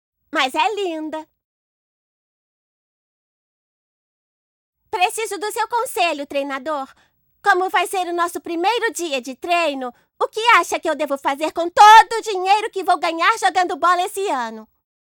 Feminino